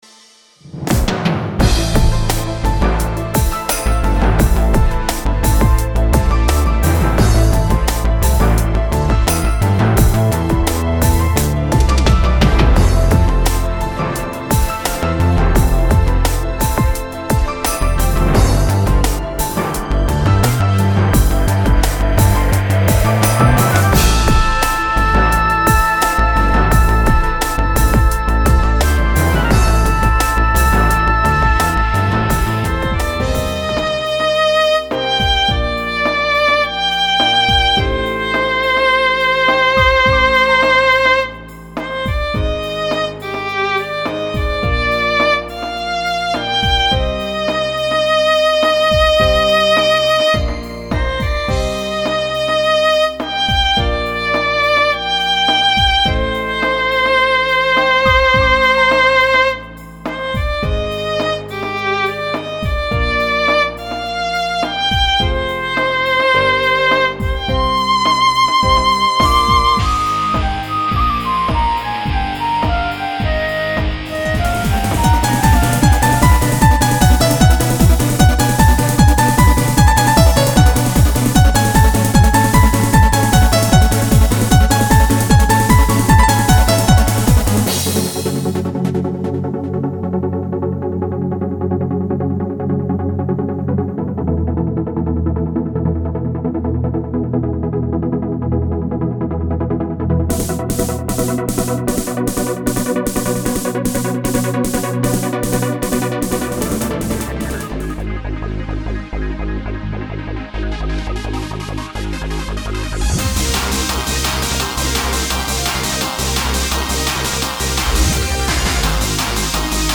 大部分已抒情為主題